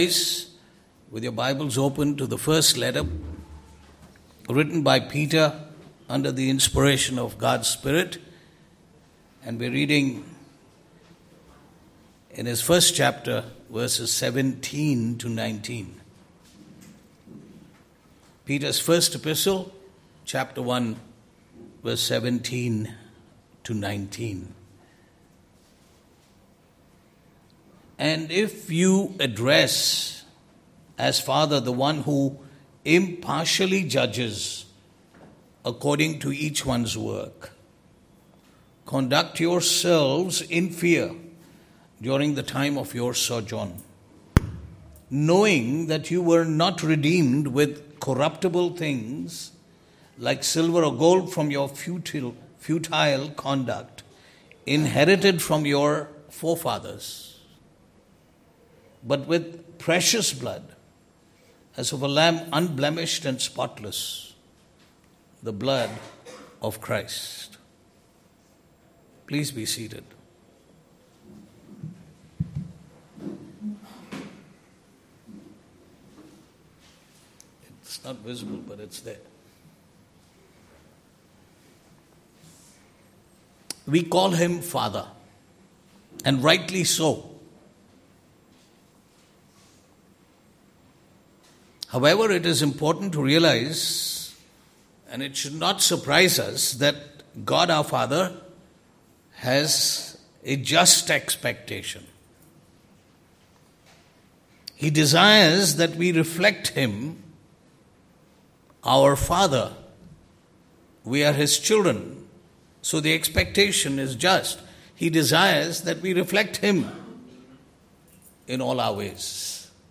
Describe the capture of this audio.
Passage: 1 Peter 1:17-19 Service Type: Sunday Morning « What Happened in the Garden of Eden?